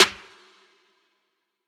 OZ - Snare 4.wav